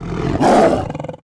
Sound / sound / monster / tiger / attack_1.wav
attack_1.wav